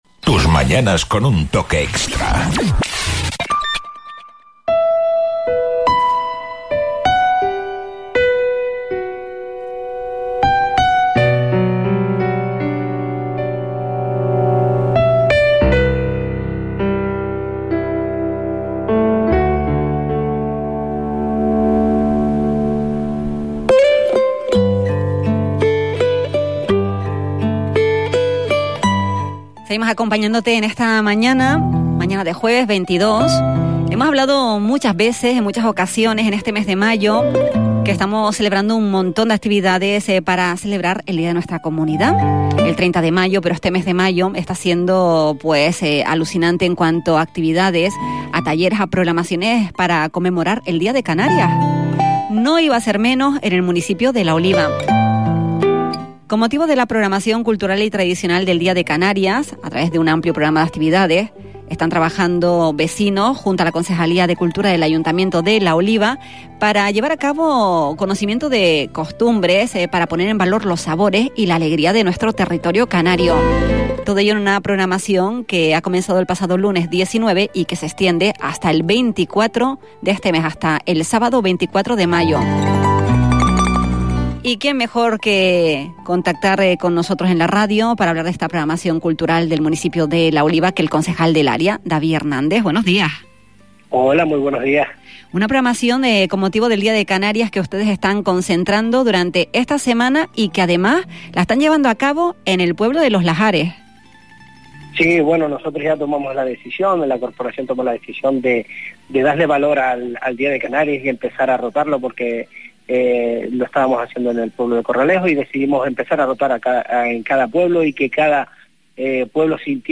Así lo explicó en una entrevista en Radio Insular el concejal de Cultura del Ayuntamiento de La Oliva, David Hernández, quien destacó la importancia de rotar cada año la sede principal de los actos: “ El año pasado lo hicimos en El Roque, la participación fue exitosa, y este año tocaba en Los Lajares ”. La programación arrancó el lunes 19 de mayo y se prolongará hasta el sábado 24, combinando talleres, charlas, actividades tradicionales, gastronomía y música.